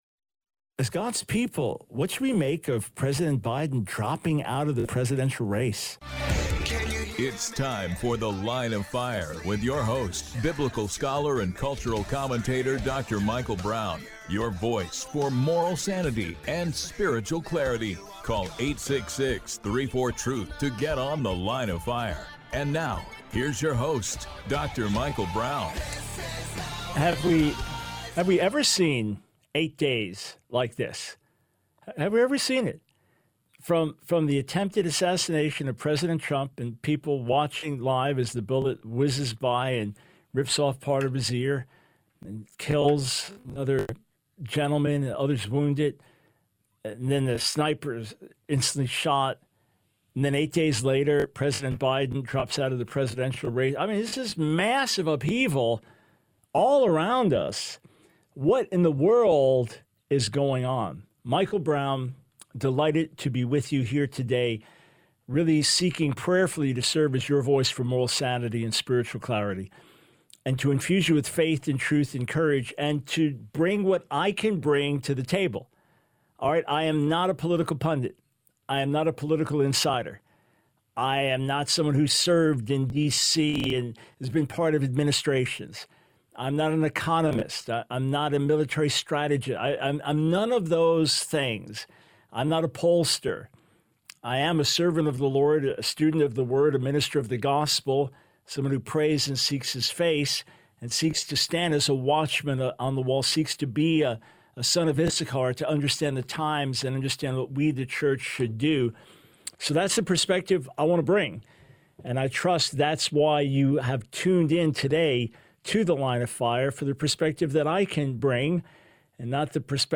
The Line of Fire Radio Broadcast for 07/22/24.